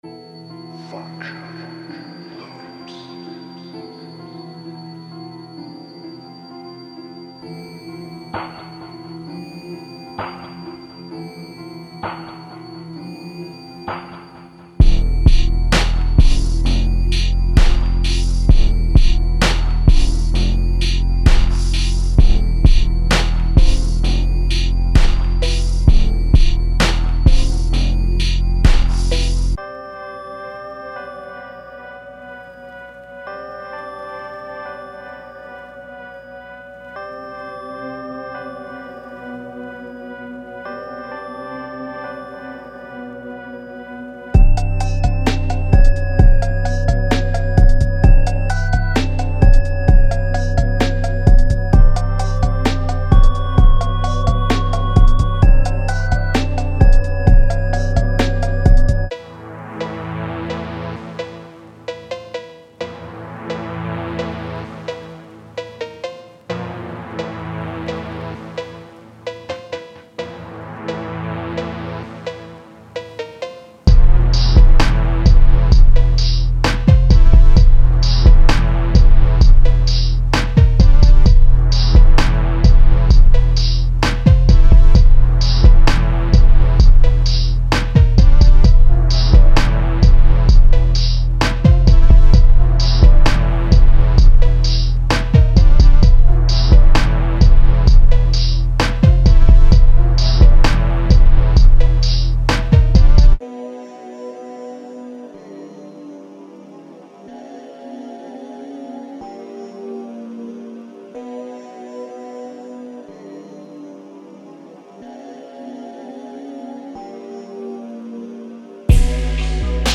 Genre:Hip Hop
デモサウンドはコチラ↓
25 Drum Loops
1 Vocal Choir Loop